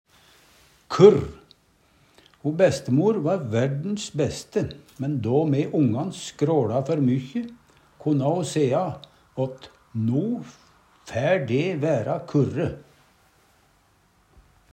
DIALEKTORD PÅ NORMERT NORSK kurr stille Eksempel på bruk Ho bestemor va verdens bæste, men då me ongan skråLa før mykkje, konna ho sea åt NO fær de væra kurre.